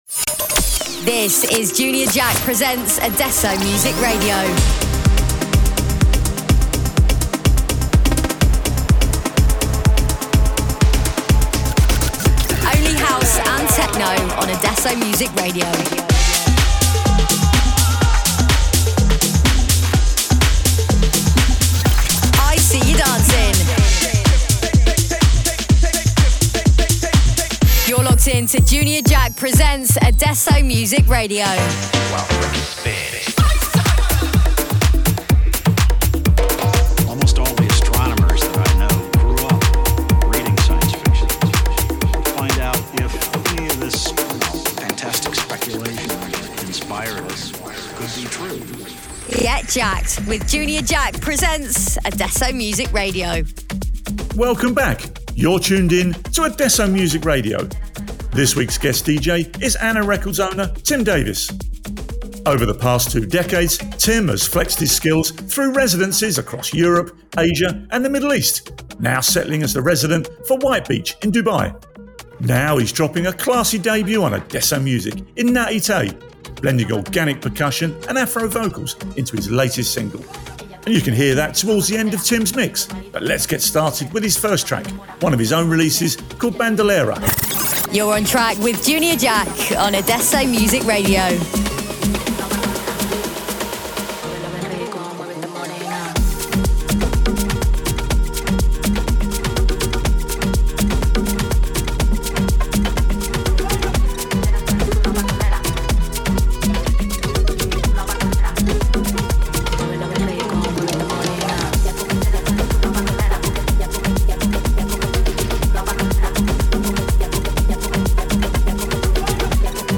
Also find other EDM Livesets, DJ